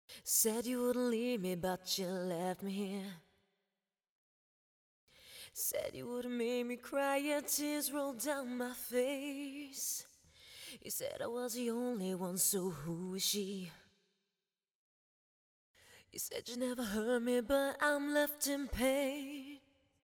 Par exemple on peut mettre une voix en avant sans pour autant équaliser à fond et avoir un effet assez jolie :
(c'est accentué hein !)